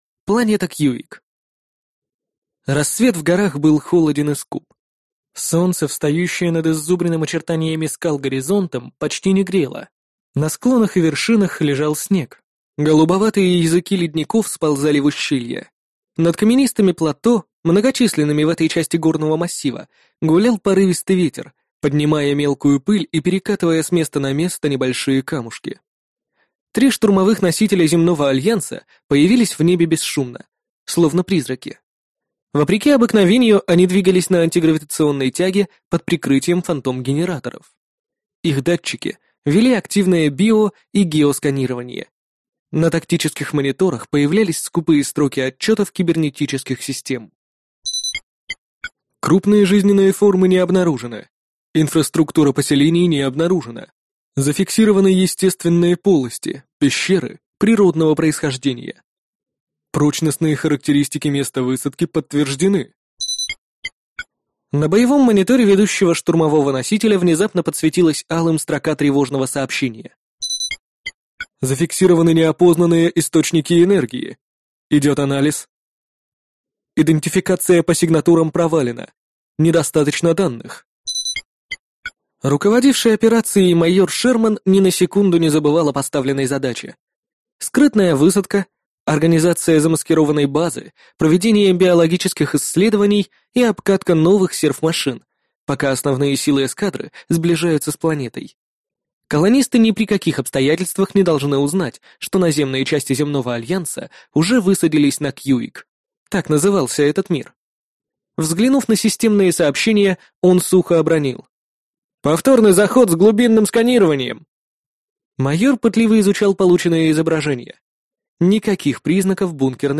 Аудиокнига Зона Отчуждения | Библиотека аудиокниг